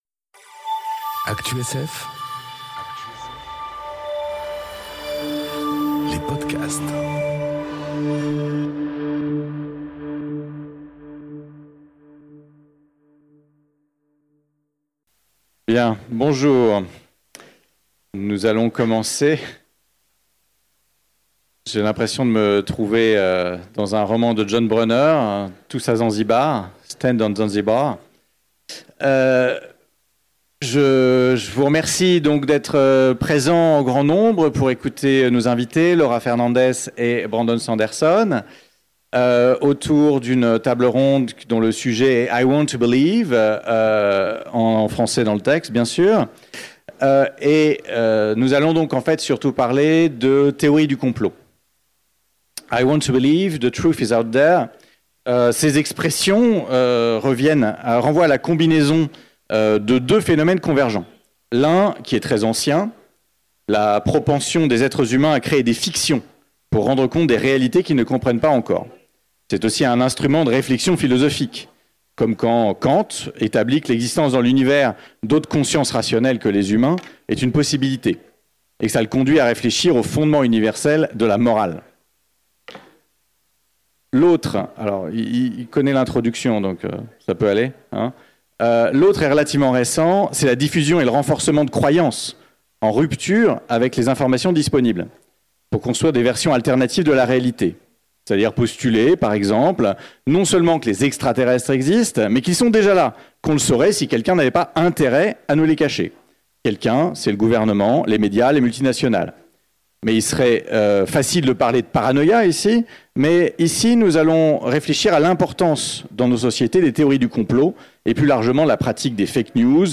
Utopiales 2019 - I want to believe